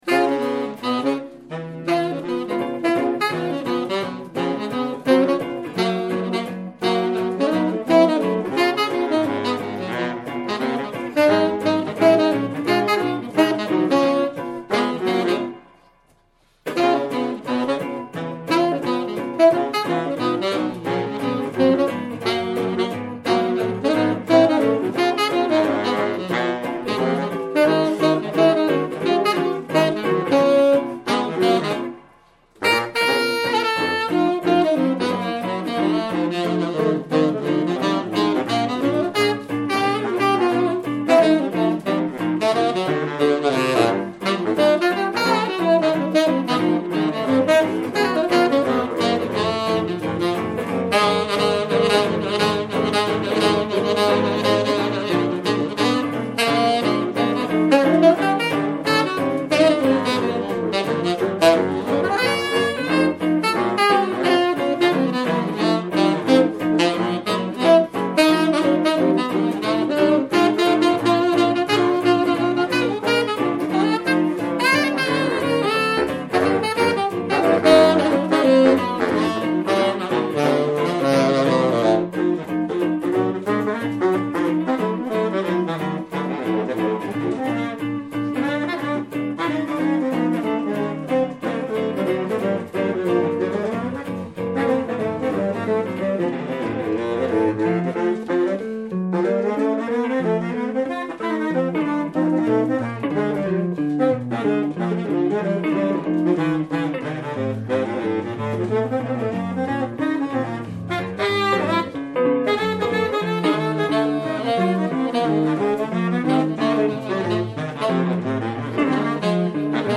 live
Cafe Express am 23.3.2013